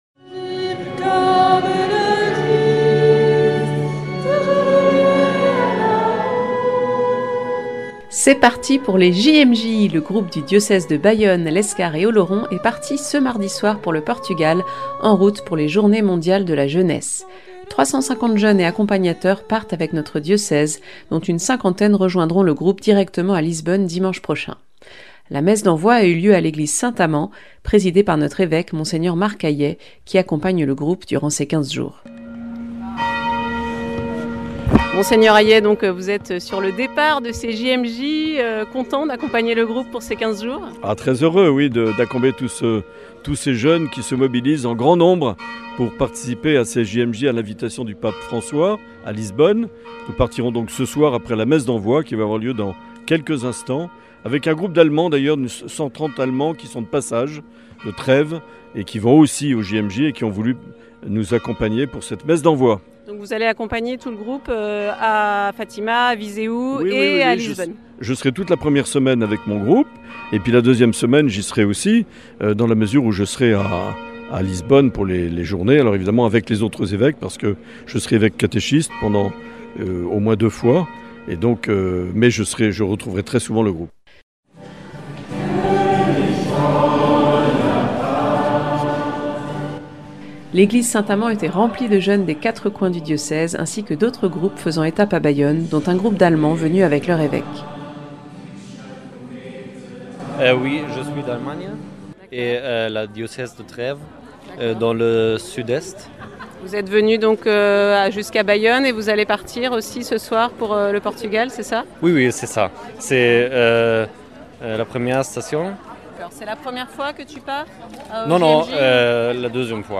Retour sur la messe d’envoi en l’église Saint-Amand de Bayonne.